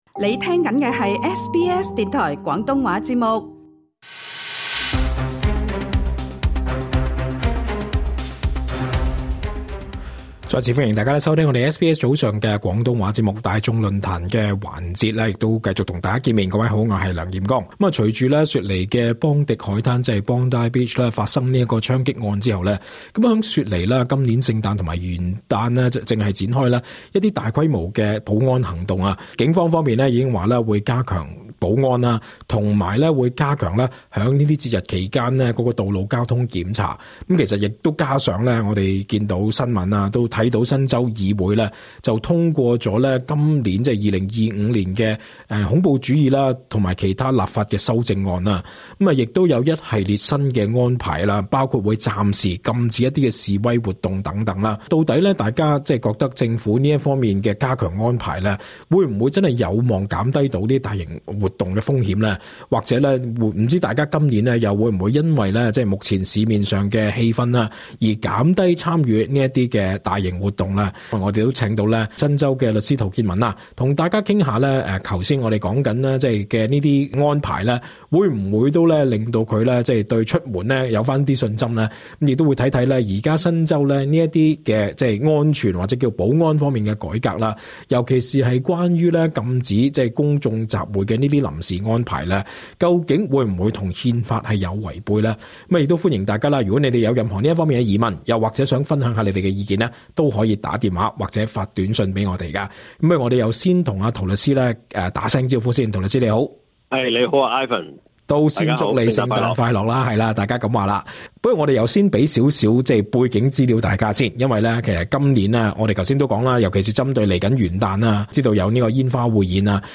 大眾論壇錄音： LISTEN TO 【除夕煙花匯演】新州警方將嚴陣以待確保安全 SBS Chinese 16:59 yue 另外，新州政府與警方已宣佈將在 12 月 31 日除夕煙花匯演期間，實施史上最大規模的保安行動。